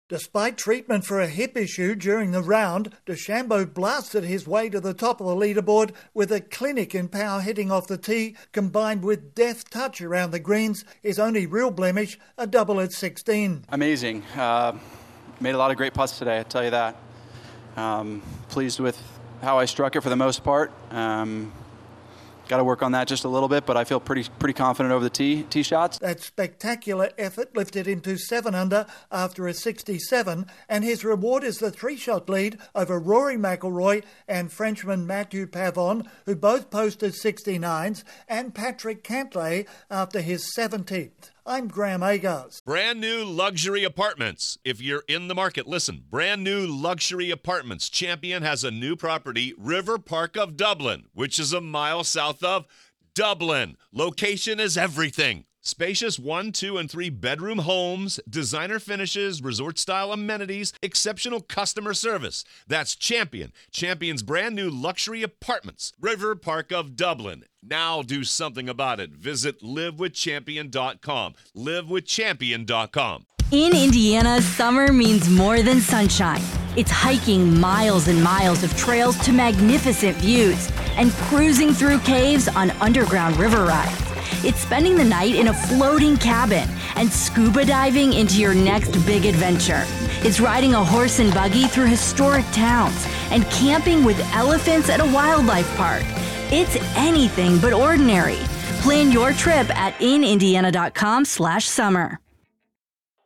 Bryson DeChambeau has pulled away to a commanding three-shot lead after the third round of the US Open Golf Championship in Pinehurst, North Carolina. Correspondent